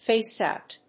アクセントfáce óut